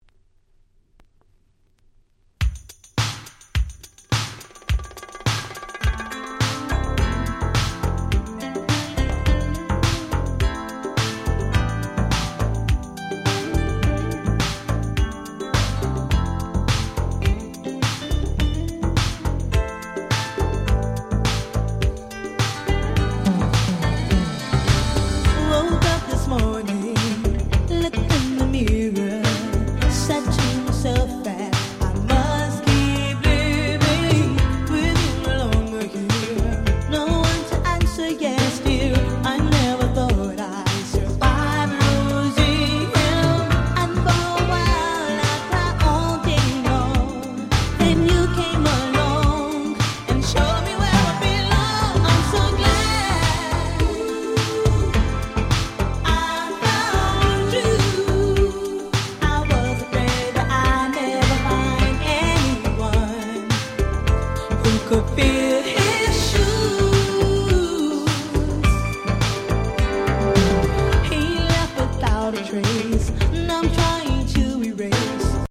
88' Super Nice UK R&B !!